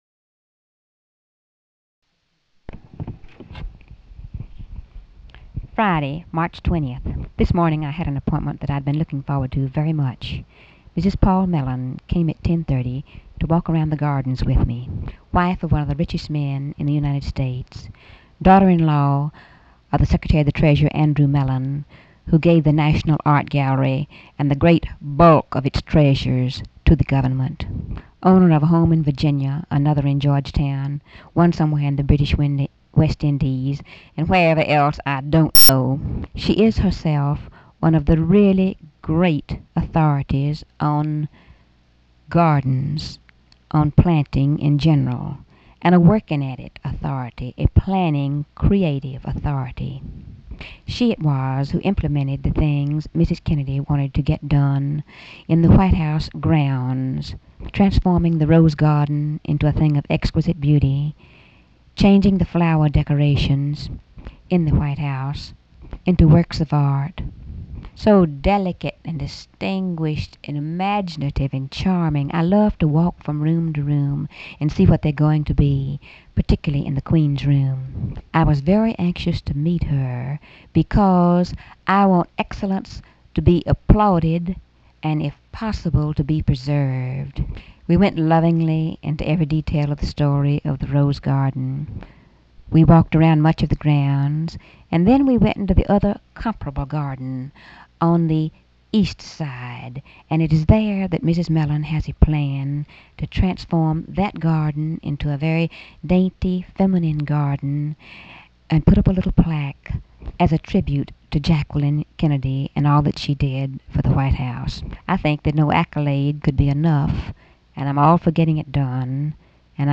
Audio diary and annotated transcript, Lady Bird Johnson, 3/20/1964 (Friday) | Discover LBJ
Audio tape
White House, Washington, DC